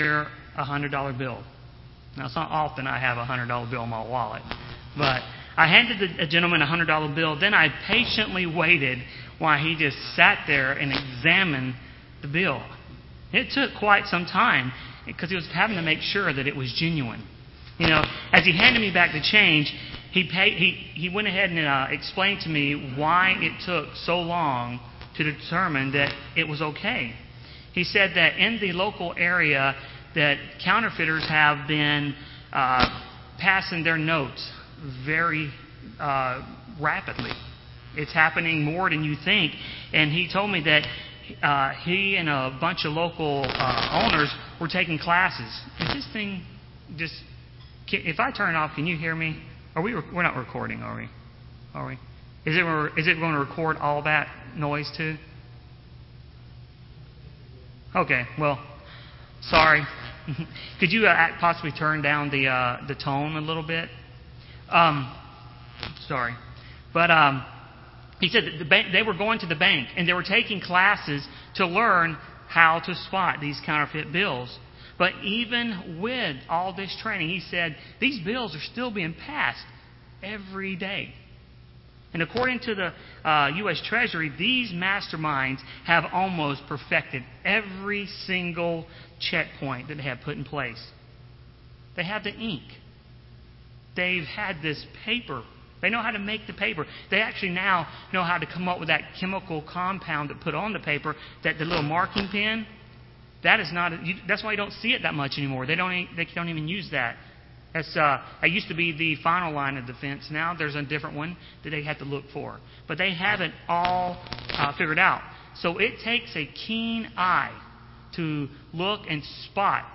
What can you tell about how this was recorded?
Given in Rome, GA